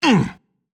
Grunt1.wav